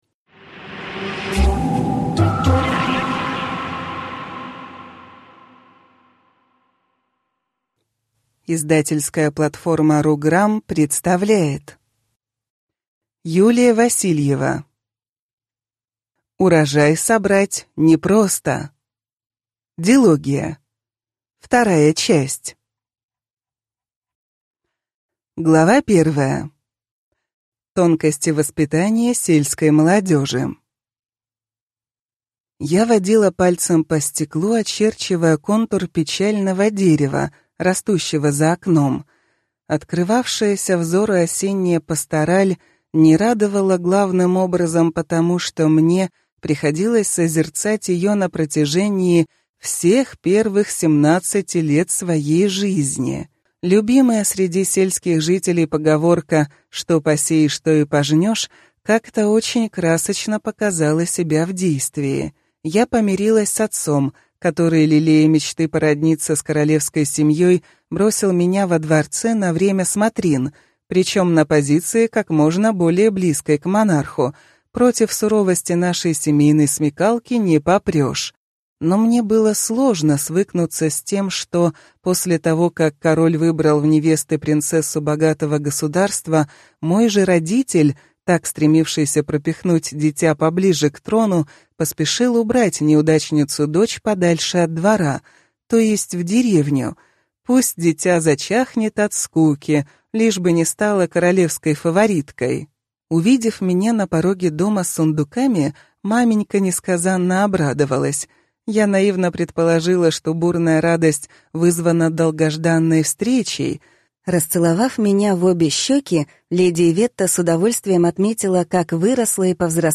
Аудиокнига Урожай собрать не просто | Библиотека аудиокниг